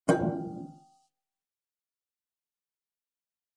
Descarga de Sonidos mp3 Gratis: clang 16.